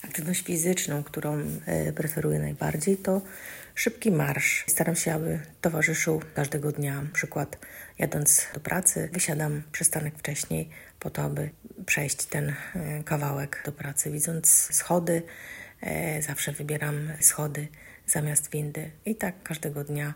Mieszkańcy Opola opowiedzieli nam, jaką formę aktywności fizycznej preferują najbardziej: